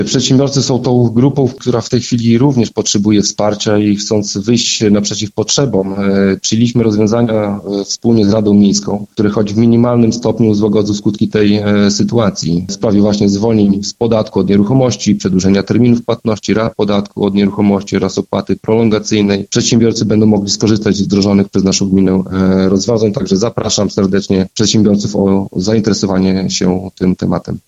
– Musimy wspierać przedsiębiorstwa i tym samym ratować miejsca pracy – mówi burmistrz Karol Sobczak.